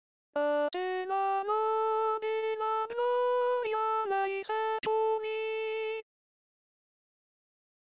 salmo